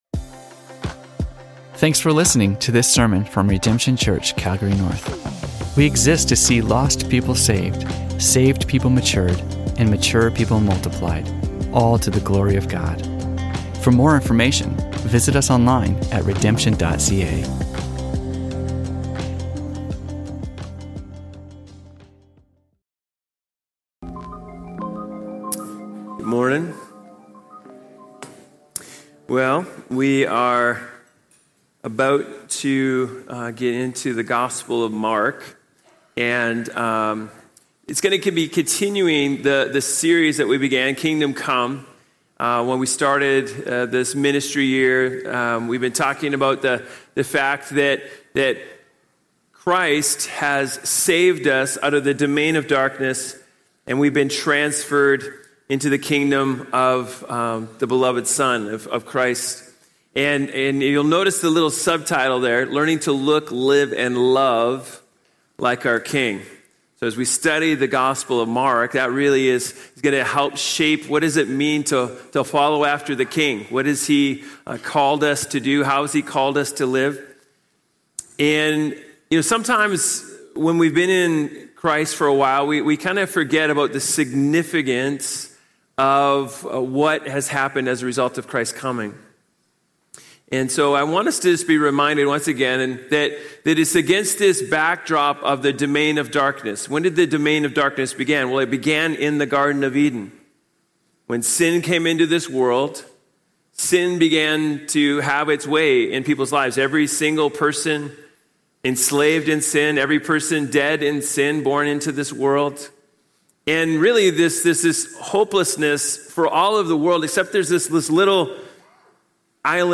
Religion Christianity Harvest Sermons Calgary Canada Redemption Church Calgary North Redemption Church Content provided by Redemption Church Calgary North and Redemption Church.